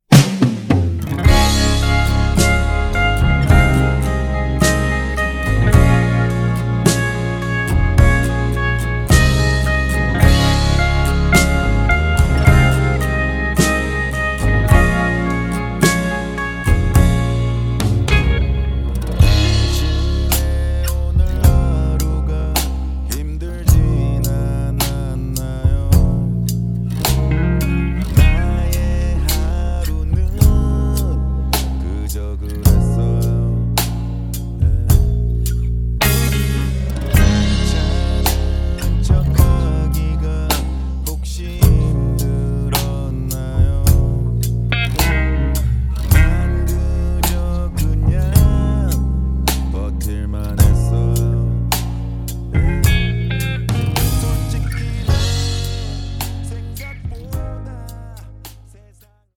음정 -1키 4:35
장르 가요 구분 Voice Cut